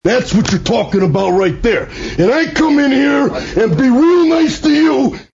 Randy Savage Talking